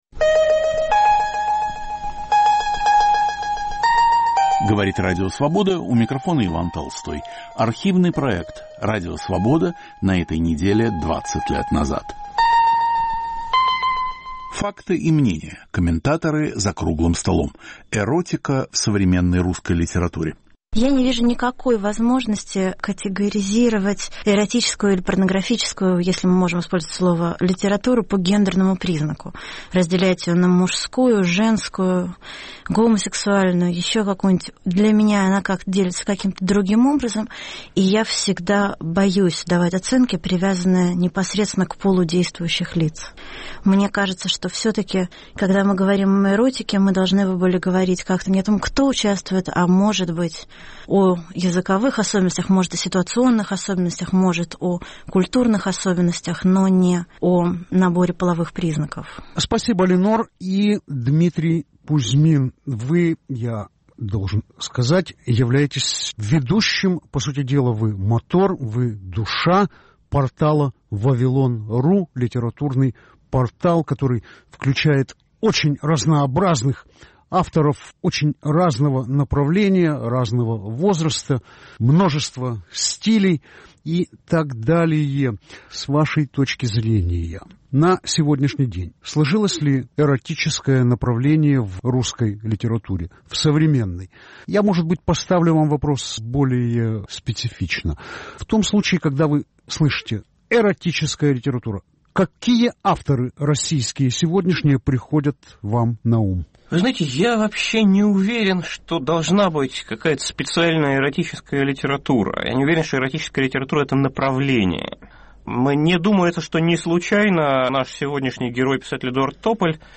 В студии Радио Свобода писатель Линор Горалик и издатель Дмитрий Кузьмин, по телефону участвует писатель Эдуард Тополь.